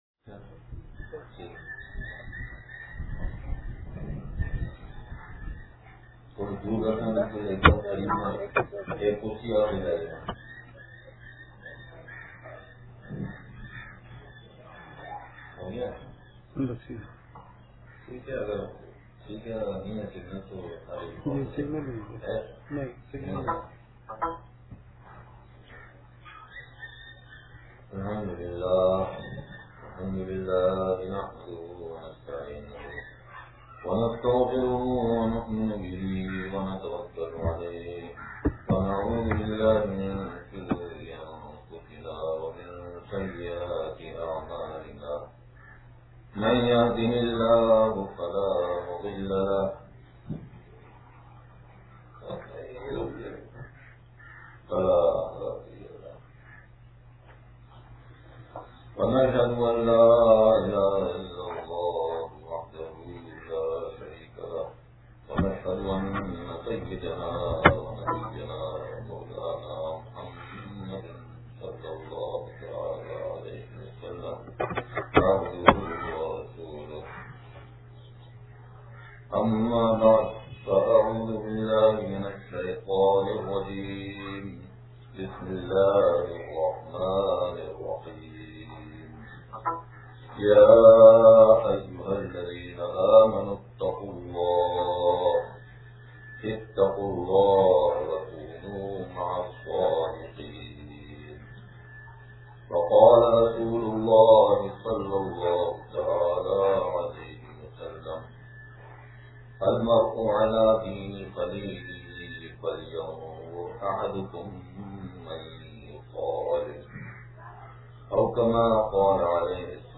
بیان – جامع مسجد بابِ رحمت جمعہ گوٹھ کورنگی نمبر 5